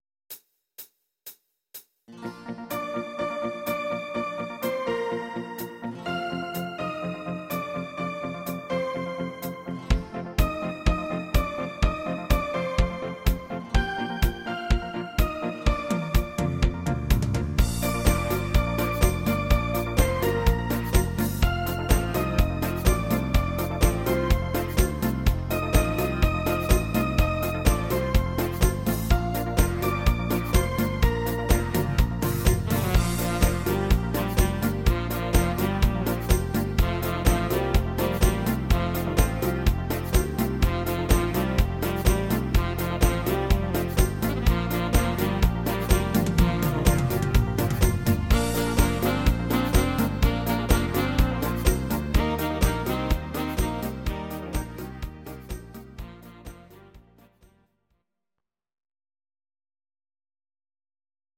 Instrumental Sax